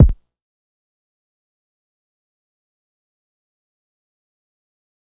Kick 8 [ torture rack ].wav